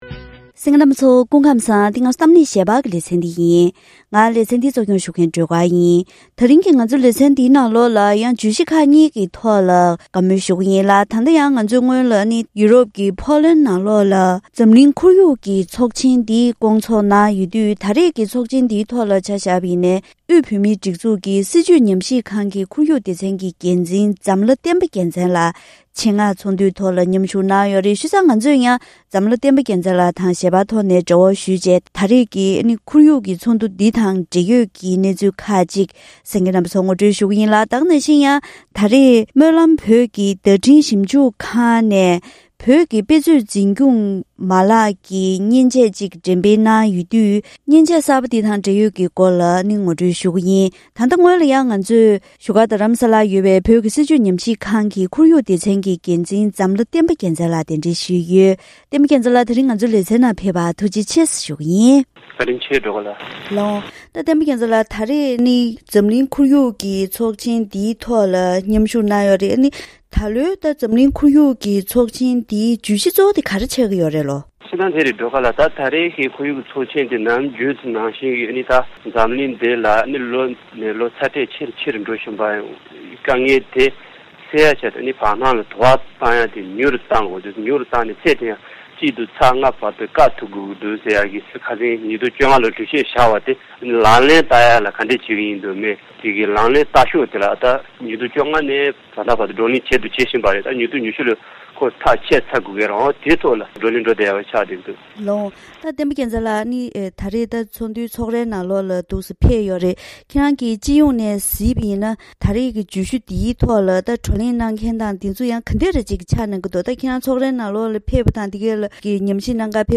བཀའ་མོལ་ཞུས་པ་ཞིག